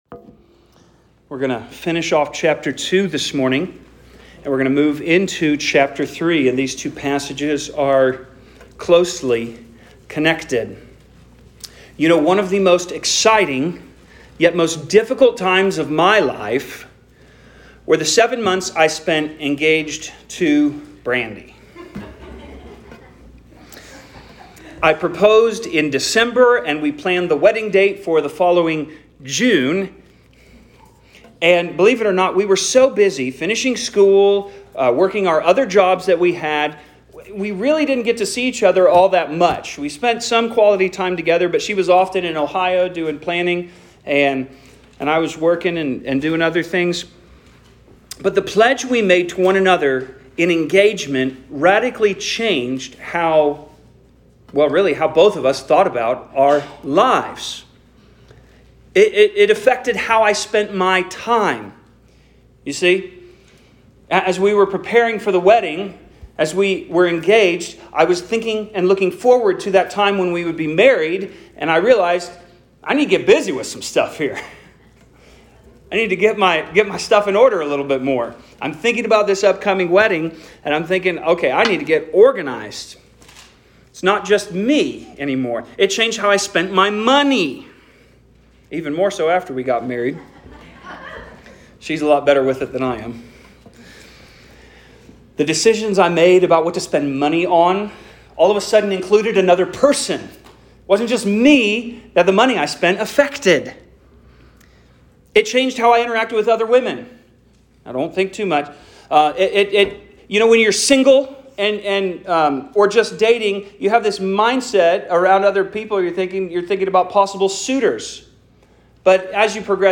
Sermons | Grace Gospel Church